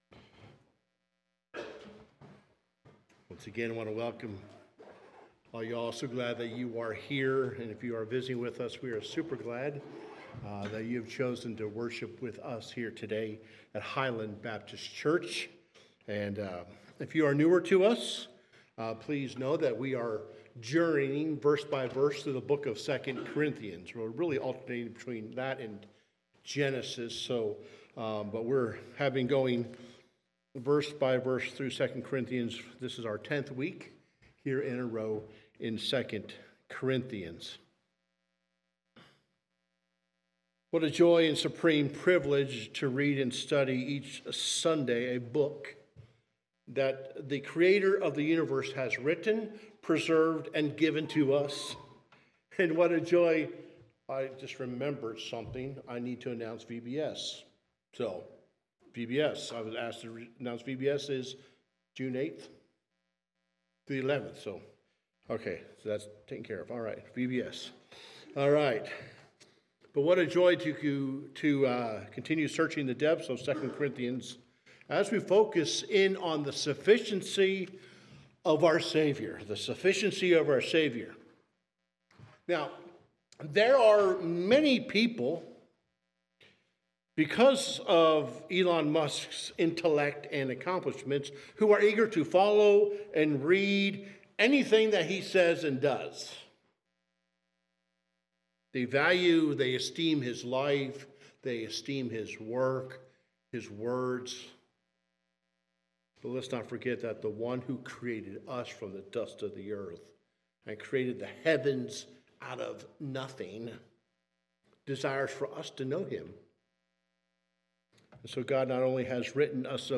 Sermons | Highland Baptist Church